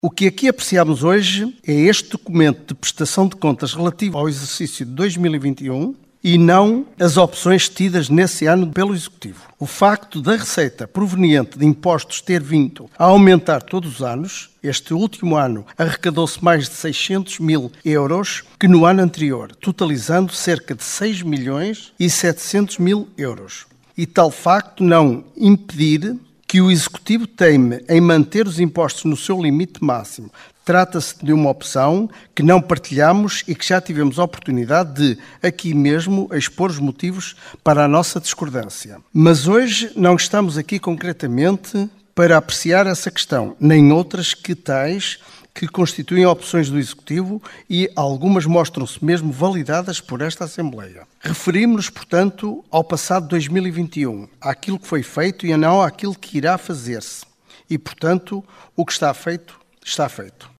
O relatório de contas relativo ao ano de 2021 foi aprovado por maioria na última Assembleia Municipal de Caminha.
Abílio Cerqueira, Bloco de Esquerda